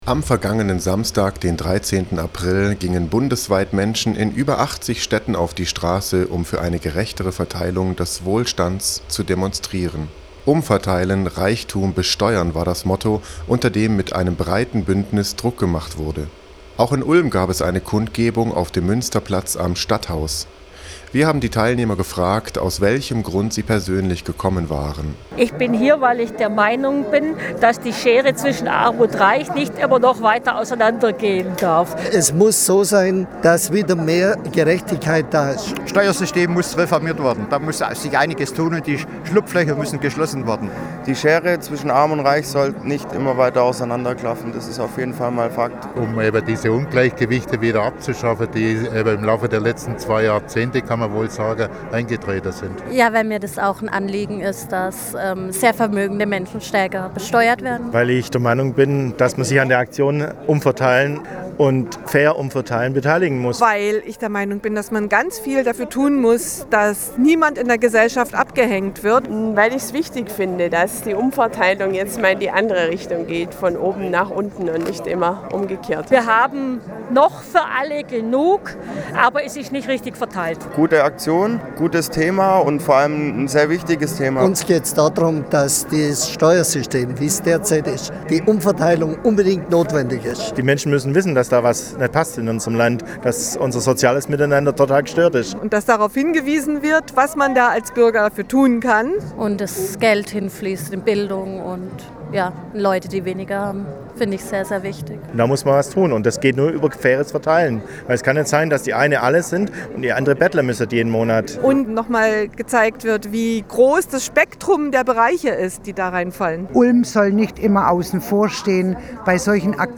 Während dem bundesweiten Aktionstag "Umfairteilen - Reichtum besteuern!" am 13. April 2013 gab es auch in Ulm eine entsprechende Kundgebung vor dem Stadthaus. Wir waren mit dem Aufnahmegerät dabei und haben eine kleine Umfrage gemacht.
umfairteilenumfrage.mp3